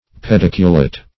pediculate - definition of pediculate - synonyms, pronunciation, spelling from Free Dictionary Search Result for " pediculate" : The Collaborative International Dictionary of English v.0.48: Pediculate \Pe*dic"u*late\, a. (Zool.)